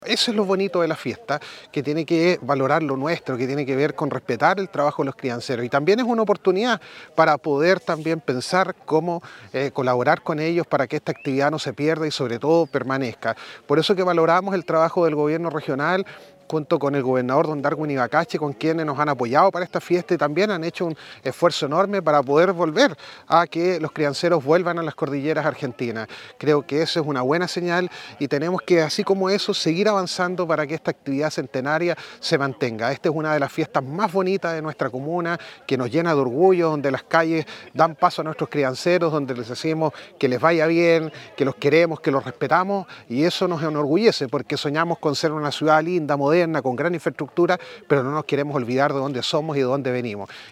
El alcalde de Illapel, Denis Cortés, quien acompañó desde temprano a los crianceros, subrayó la relevancia de toda la actividad.
ALCALDE-DE-ILLAPEL-DENIS-CORTES.mp3